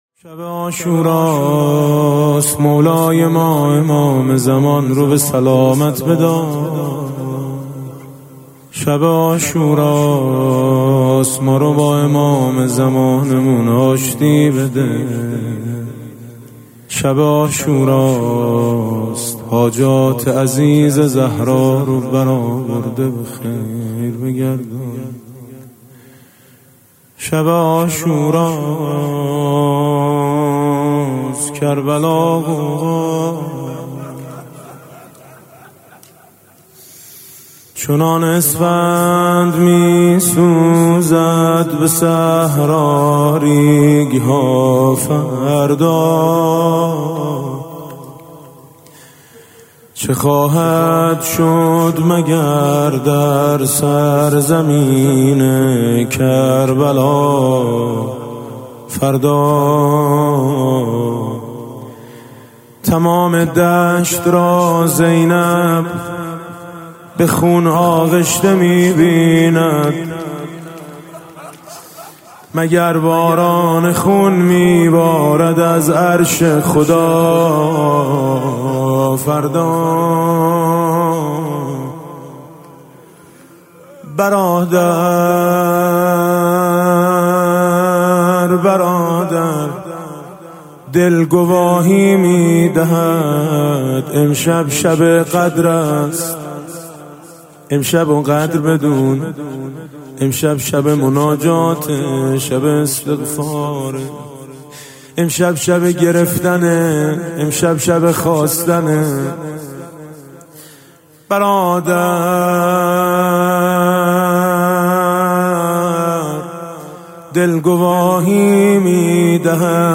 محرم 99 - شب عاشورا - روضه - تمام دشت را زینب به خون آغشته می بیند